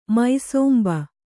♪ mai sōmba